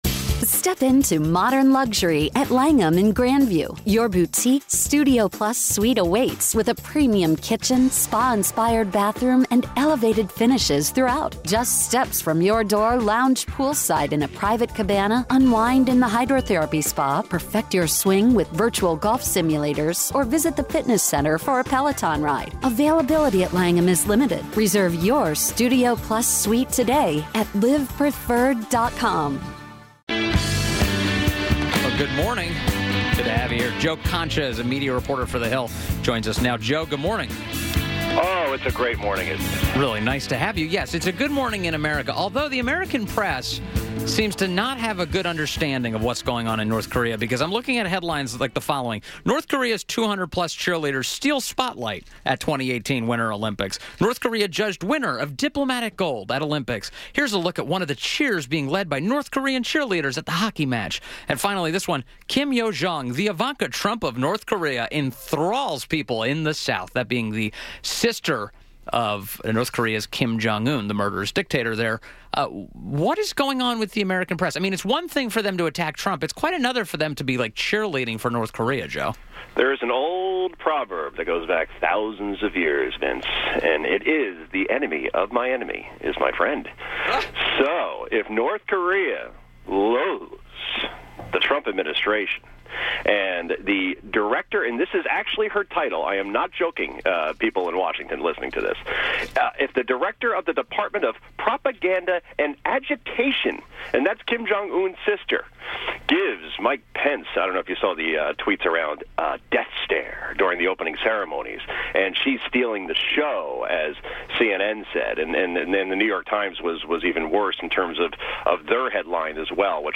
WMAL Interview
INTERVIEW